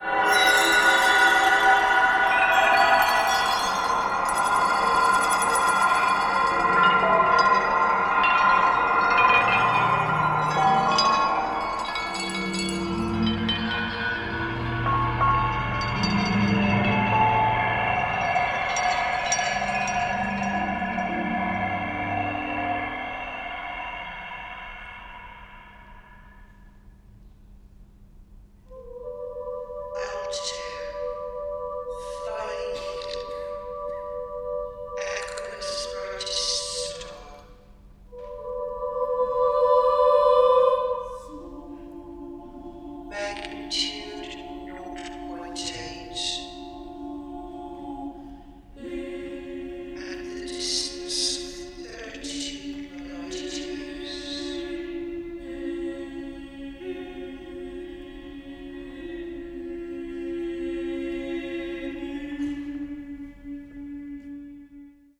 for vocal ensemble and electronics.